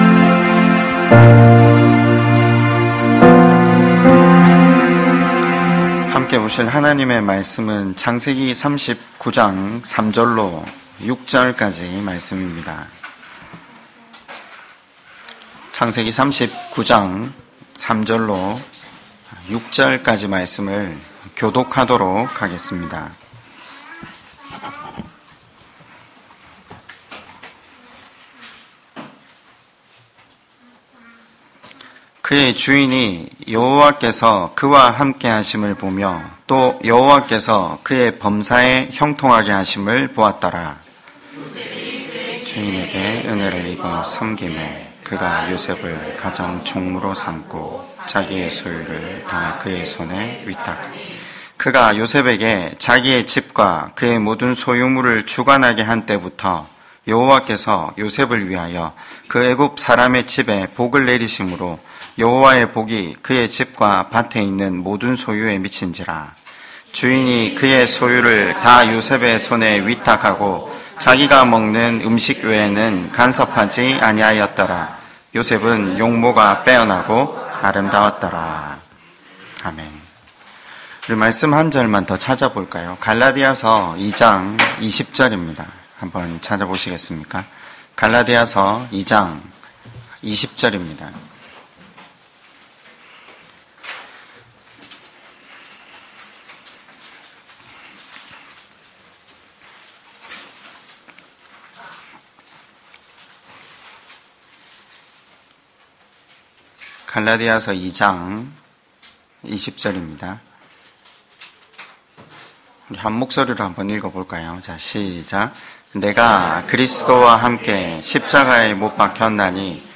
2020년 2월 9일 주일설교 (만사형통2 _ 창 39장 3-6절).mp3